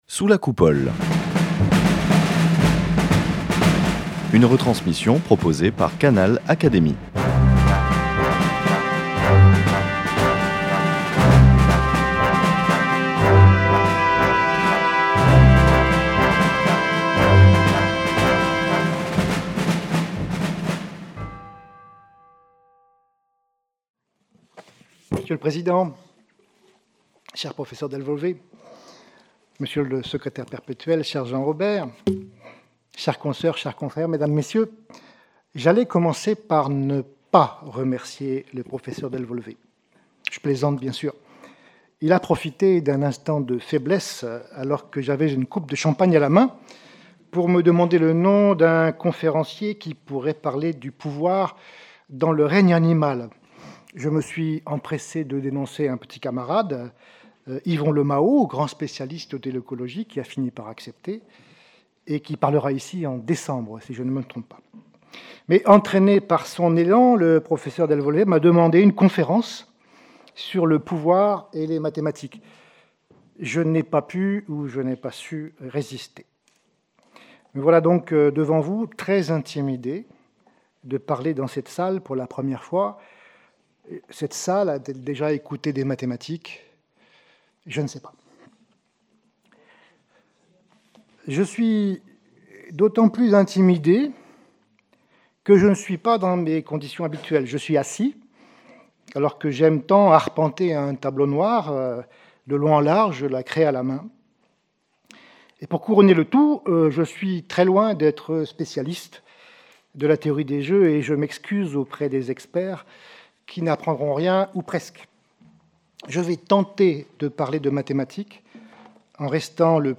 Dans son allocution , l’orateur propose une histoire de la théorie des jeux explique comment elle peut apporter un éclairage sur les comportements d’agents qui interagissent en compétition.